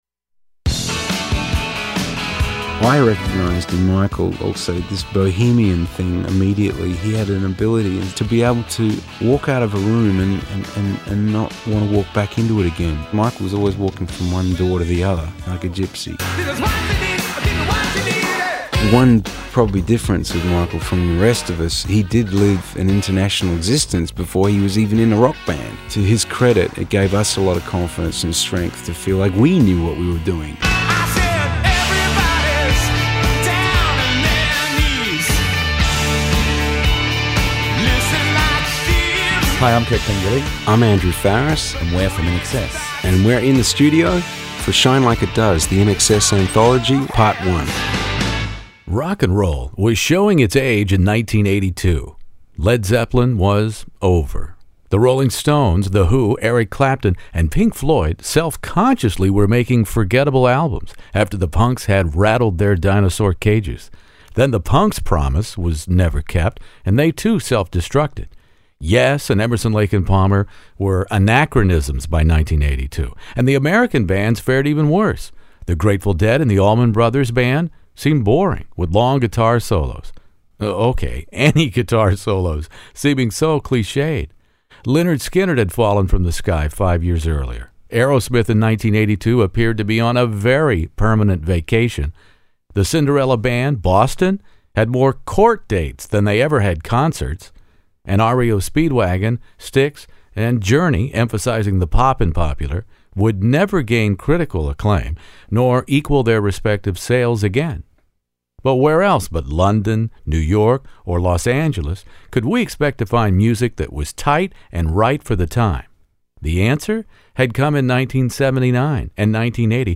INXS “Listen Like Thieves” interview In the Studio